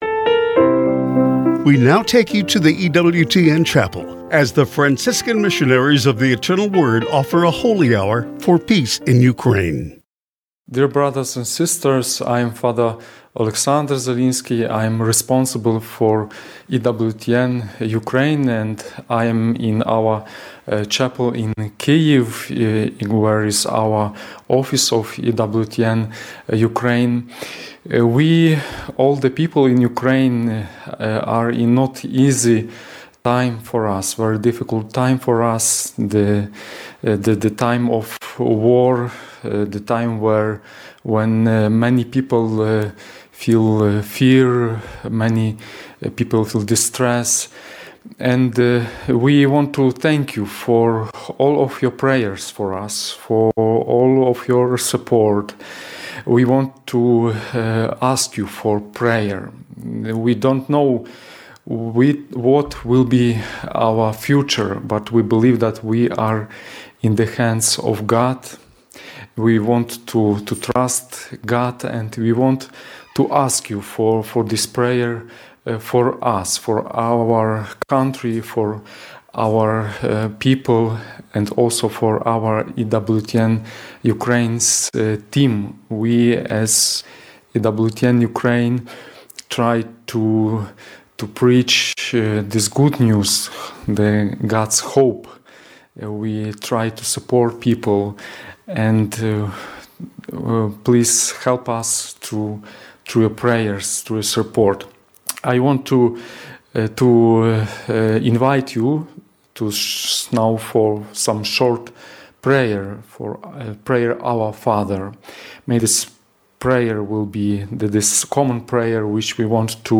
The MFVA Friars offer a Holy Hour for Peace in Ukraine